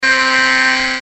shaosheng2.mp3